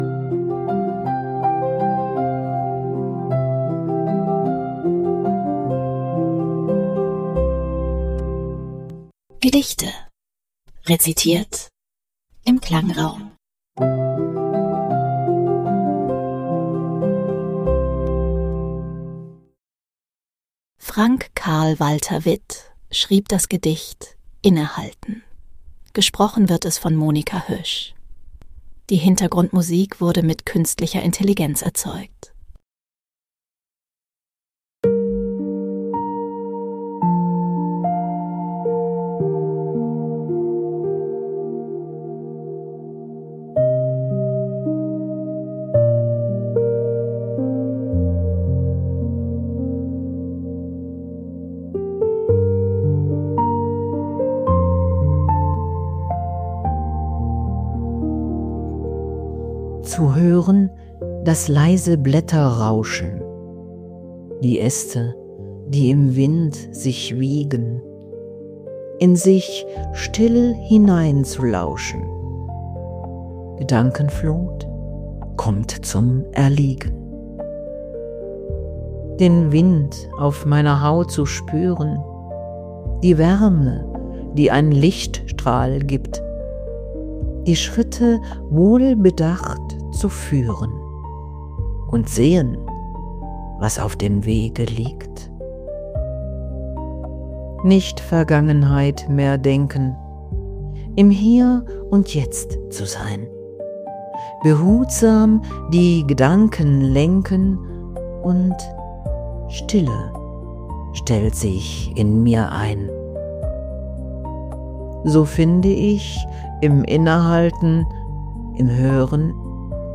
Begleitmusik wurde mithilfe Künstlicher Intelligenz erzeugt.